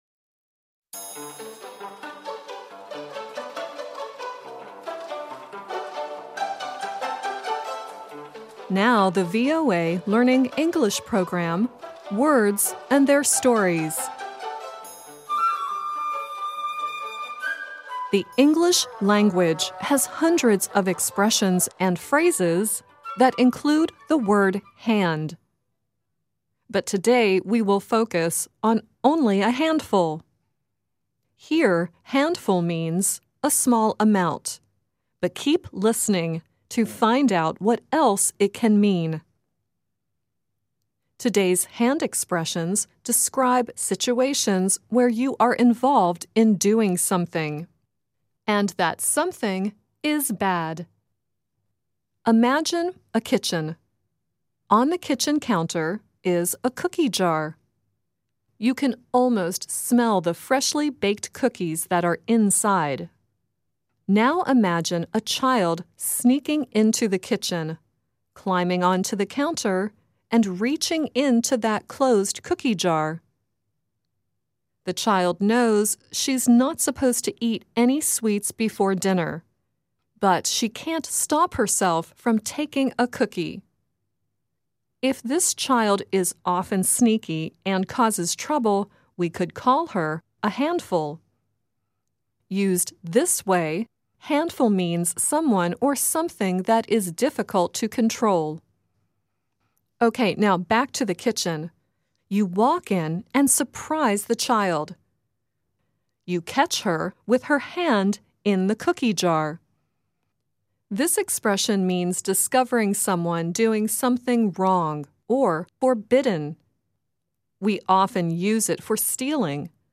Kalau bisa diusahakan bacanya mirip dengan native speaker dibawah ini, oke..
The song at the end is from the television show Sesame Street and features Cookie Monster, Elmo and Abby singing “Who Stole the Cookie.”